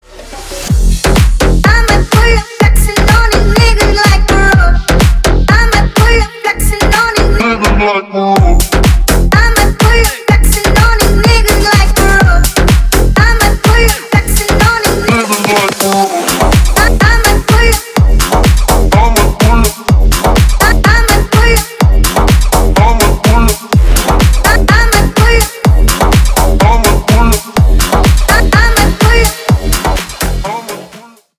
Ремикс
Поп Музыка
клубные